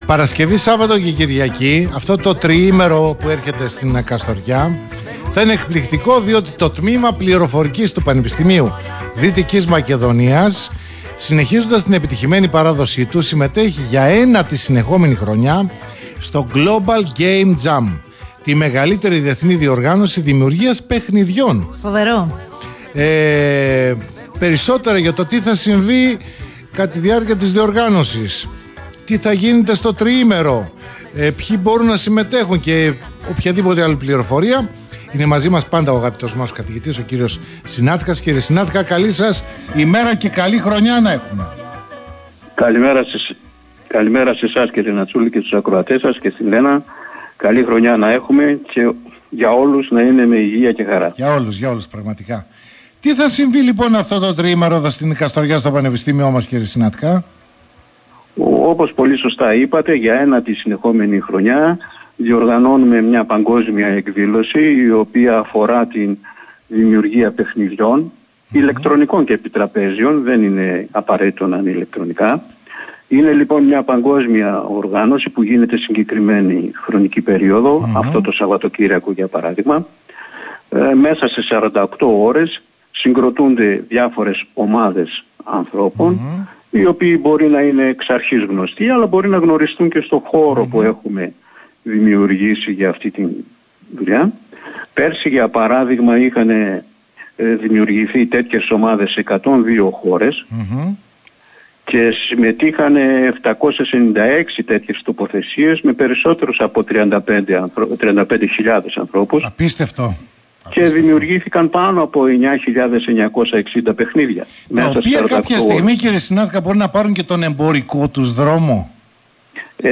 Μόνο σε Αθήνα και σε Καστοριά από σήμερα το non-stop τριήμερο “9ο Global Game Jam 2025” – Συνέντευξη